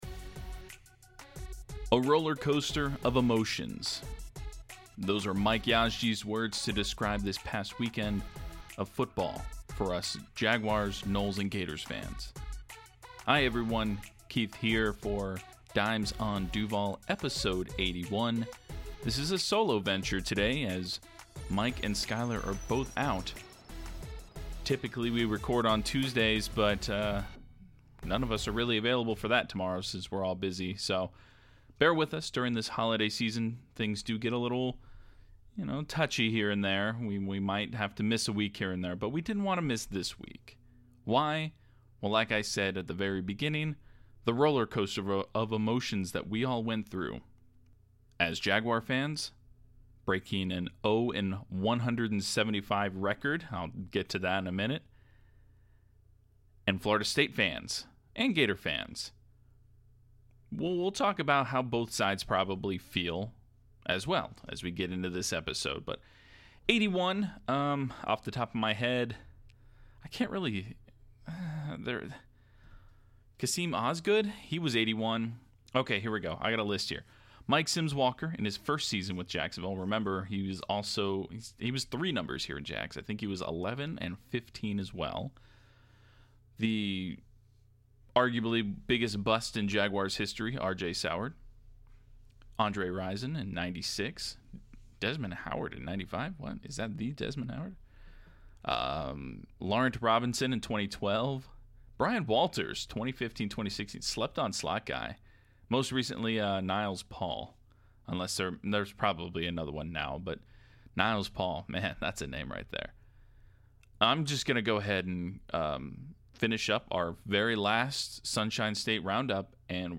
Solo pod alert!